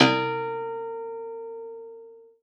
53w-pno04-A2.wav